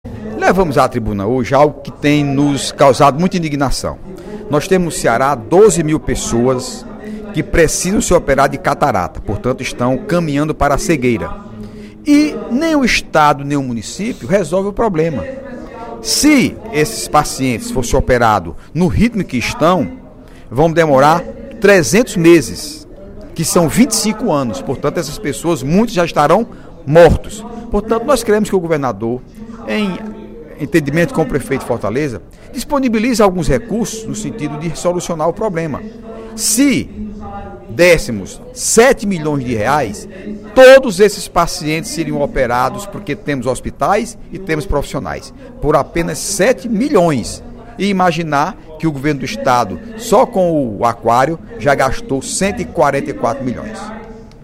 O deputado Heitor Férrer (PSB) cobrou, durante o primeiro expediente da sessão plenária desta sexta-feira (11/03), recursos para a realização de 12 mil cirurgias de catarata que se encontram em fila de espera nos hospitais públicos cearenses.